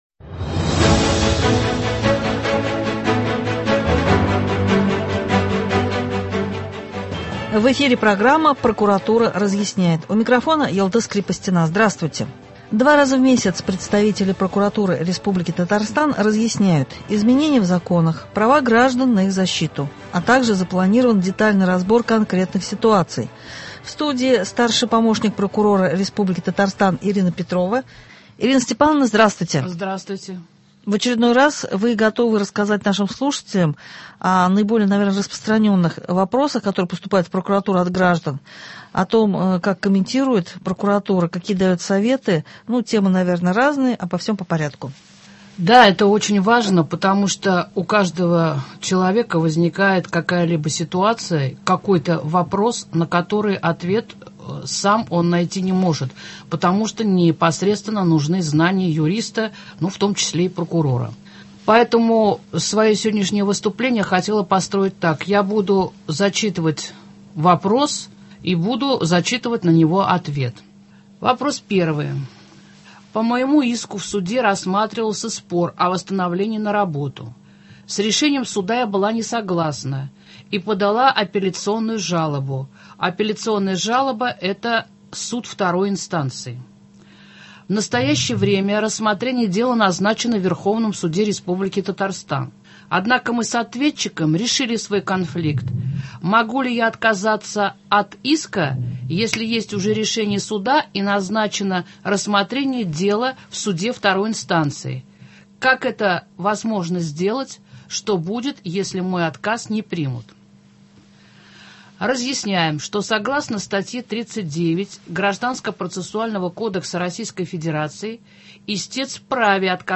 В рамках программы представители Прокуратуры Республики Татарстан разъясняют: изменения в законах, права граждан на их защиту, также проводится детальный разбор конкретных ситуаций. В студии